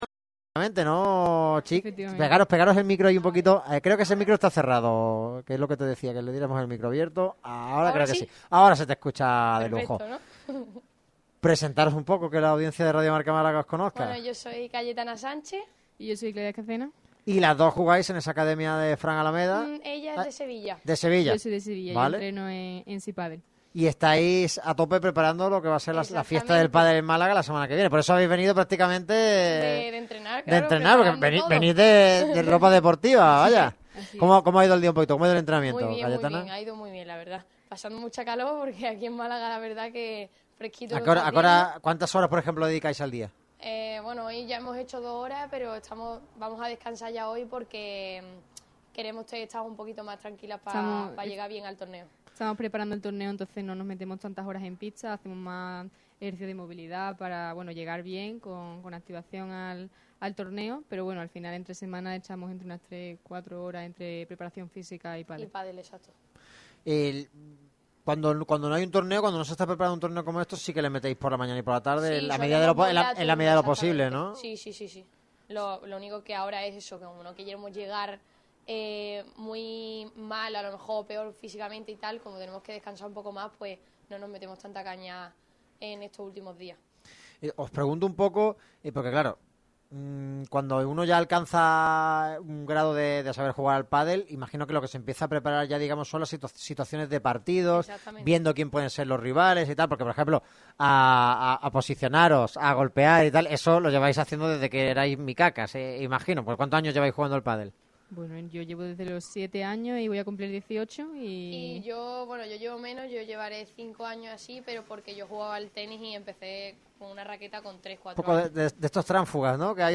ENT-PADEL.mp3